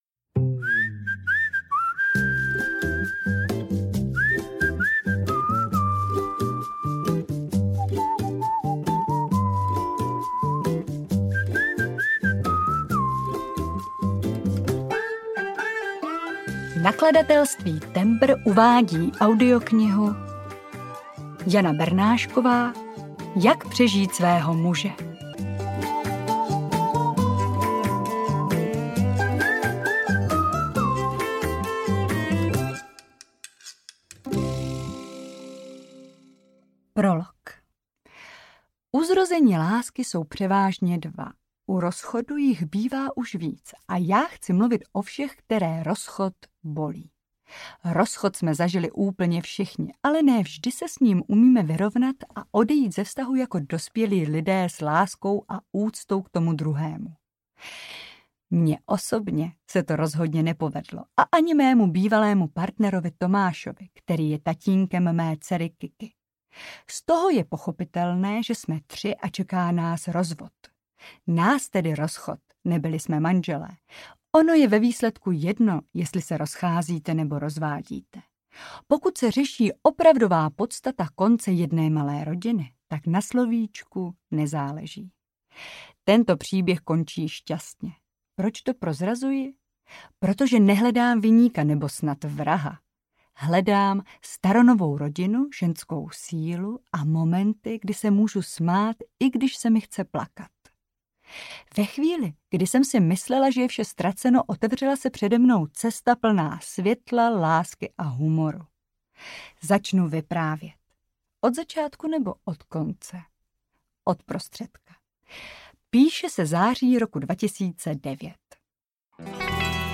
Jak přežít svého muže audiokniha
Ukázka z knihy